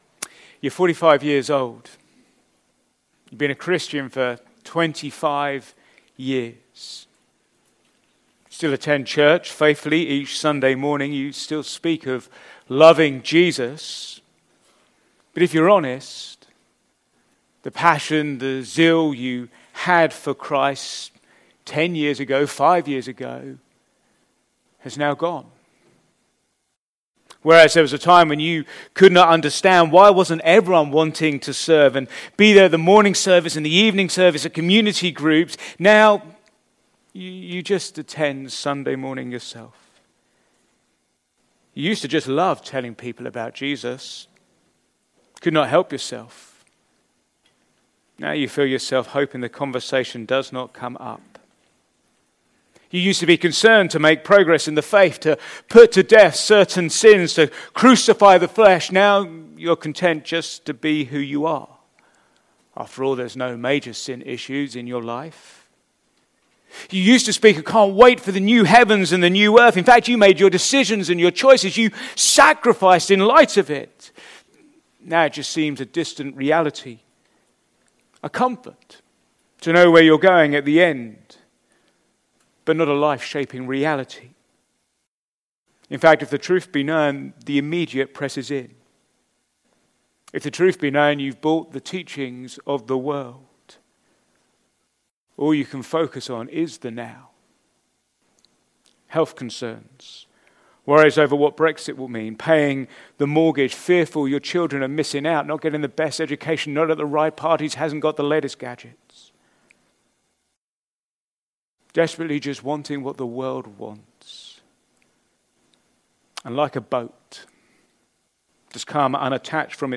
Back to Sermons Boasting in the Spirit